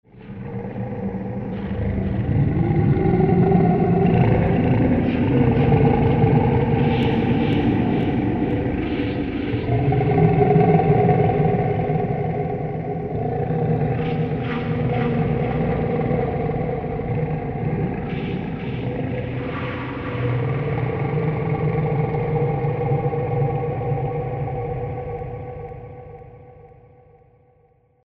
zvuk_afa.mp3